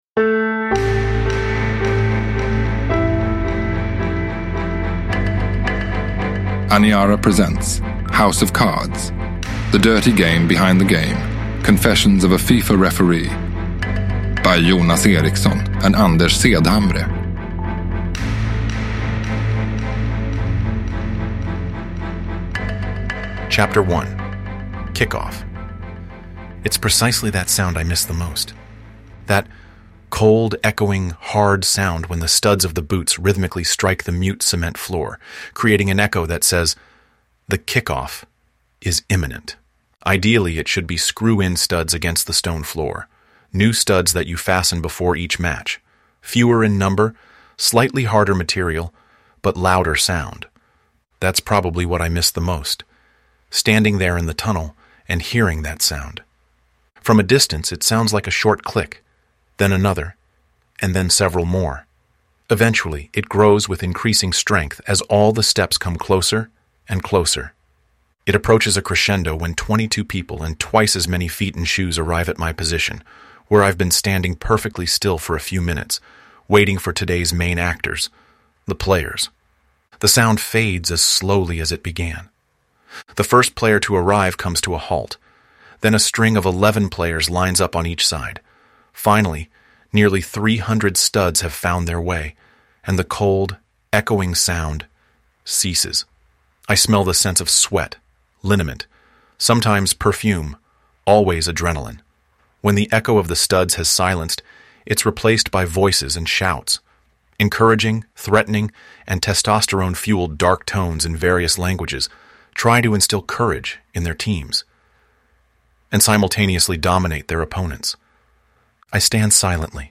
House of Cards / Ljudbok